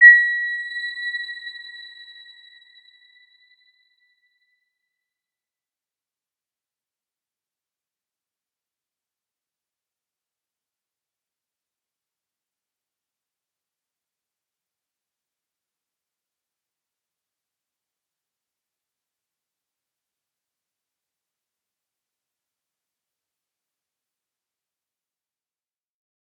Round-Bell-B6-mf.wav